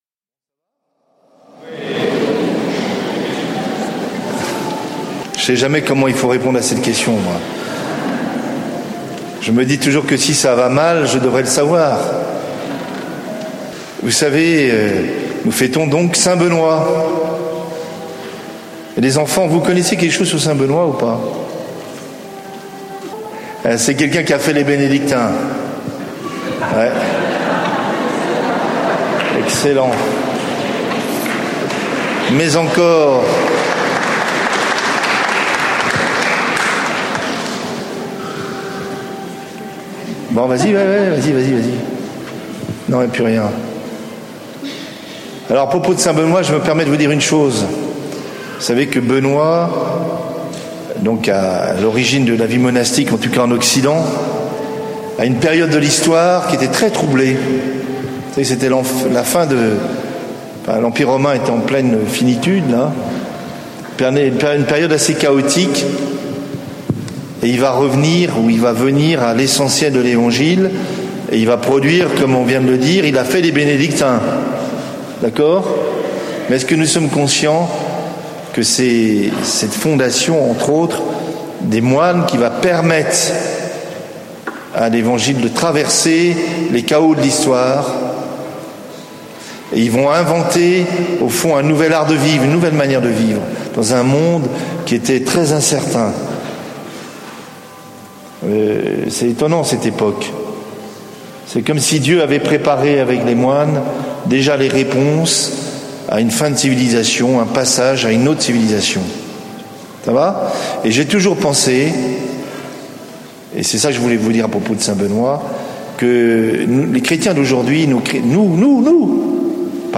Enregistré en 2015 (Session Béatitudes)
Format :MP3 64Kbps Mono
03. (16:57mn Mgr Robert le Gall) Lourdes 2015 - Homélie 3/4 (1.50 EUR)